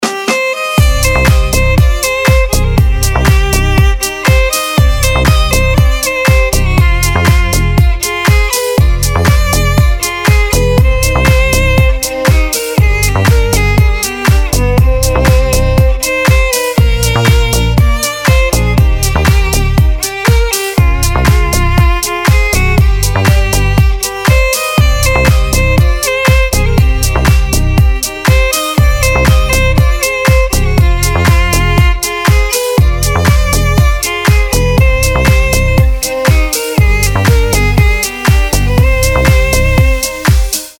• Качество: 320, Stereo
deep house
мелодичные
без слов
красивая мелодия
скрипка
Violin